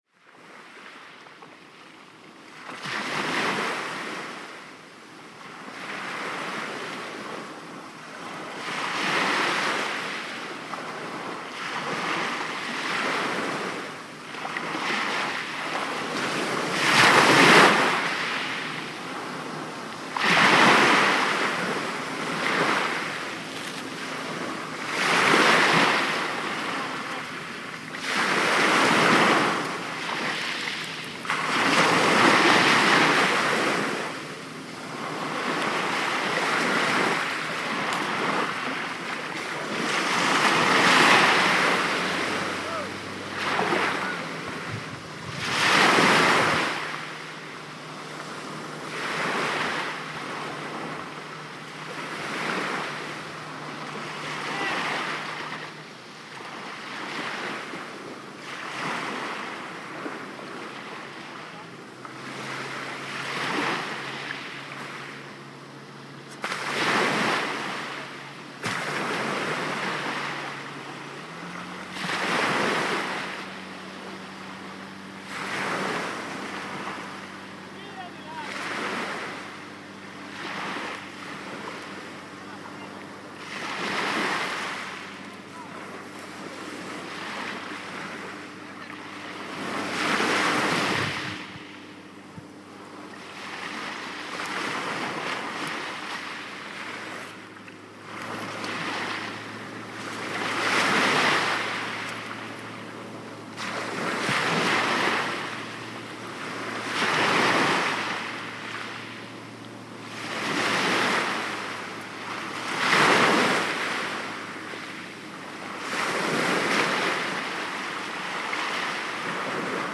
Sea at Stretto di Messina